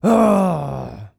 Male_Grunt_Attack_01.wav